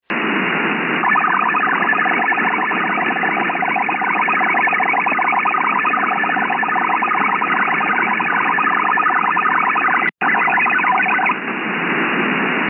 That’s just a short digital identification burst saying “This is PLA!” Here’s a recording of an ALE sounding.
That’s not the kind of signal that anyone would enjoy listening to all day.
Sample_ALE_Sounding.mp3